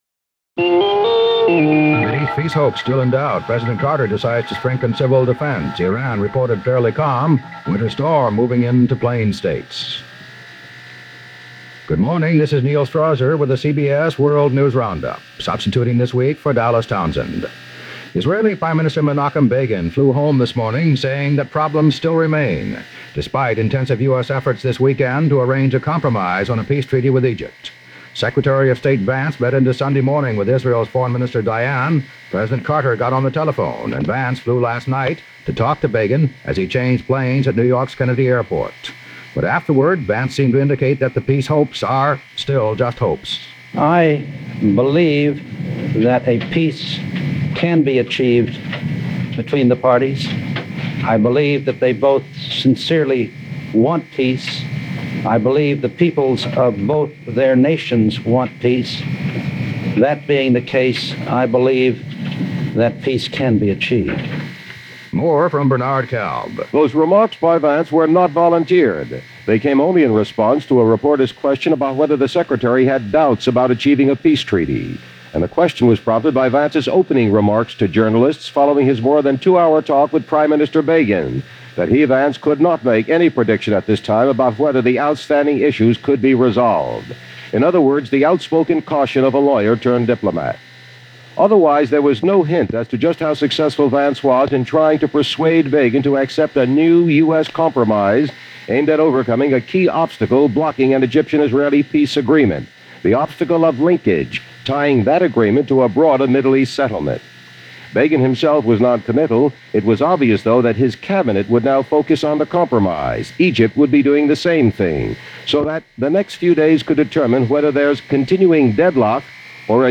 CBS World News Roundup – 9:00 am Network News